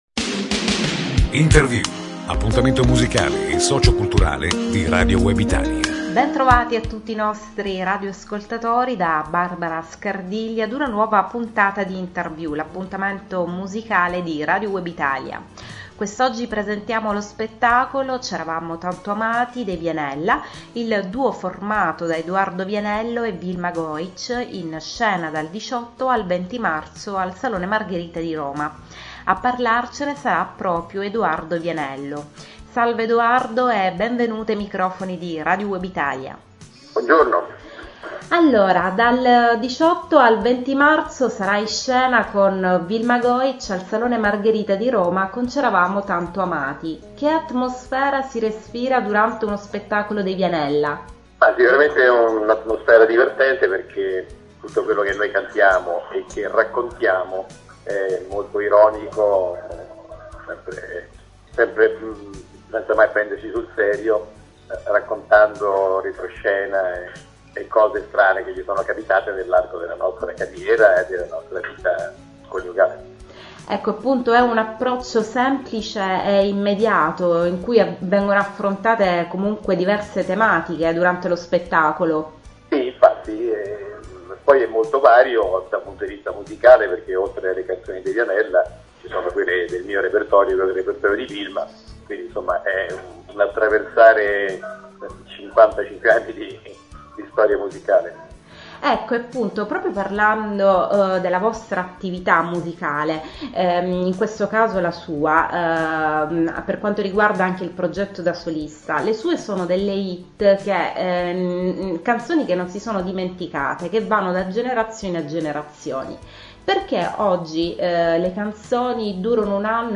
Intervista ai Vianella, il duo composto da Edoardo Vianello e Wilma Goich
Edoardo Vianello sarà ospite dei microfoni di ‘Interview’ – appuntamento musicale di Radio Web Italia – mercoledì 16 marzo ore 12.00